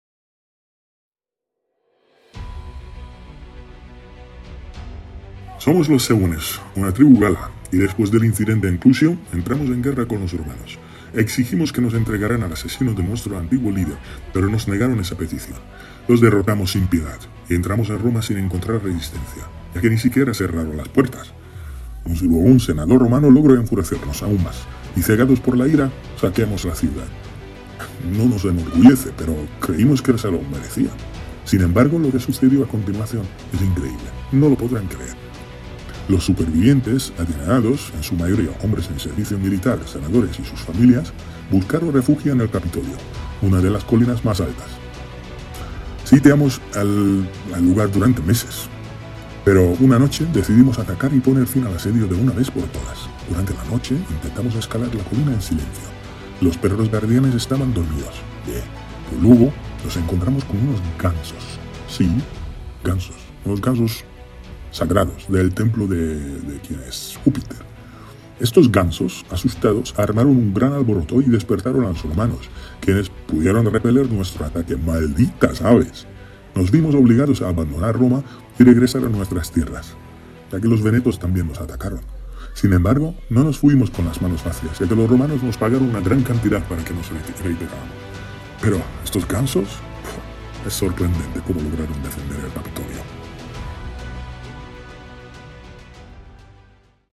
Breno, jefe de los galos, discute la leyenda de los gansos del Capitolio